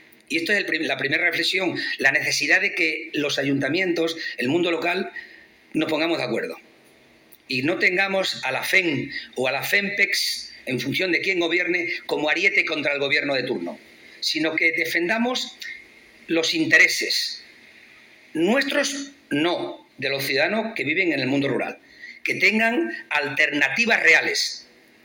Miguel Ángel Morales inaugura el II Foro de Municipalismo, en el que defiende el papel de alcaldes y alcaldesas, “que asumen competencias que no les corresponden, por lo que hay que clarificar la financiación”
CORTES DE VOZ